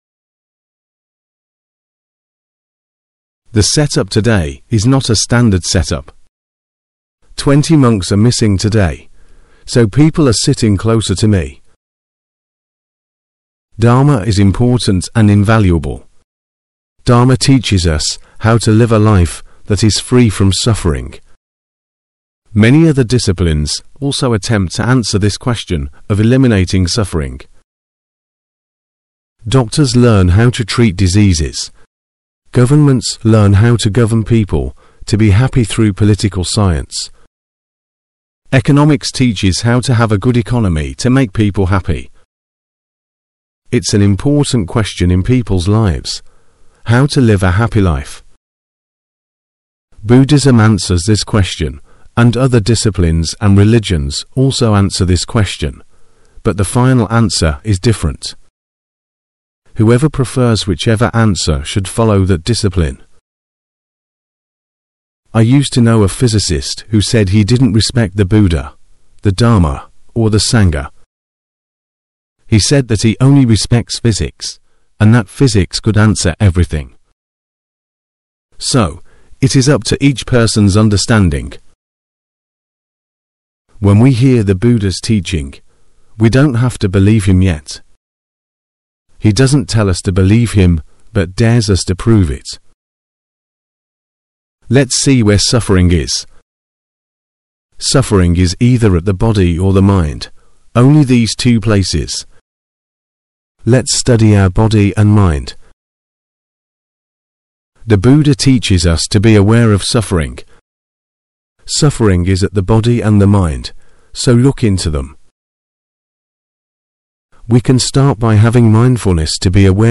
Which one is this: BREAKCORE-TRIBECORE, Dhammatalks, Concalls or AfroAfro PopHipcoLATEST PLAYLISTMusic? Dhammatalks